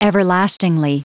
Prononciation du mot everlastingly en anglais (fichier audio)
Prononciation du mot : everlastingly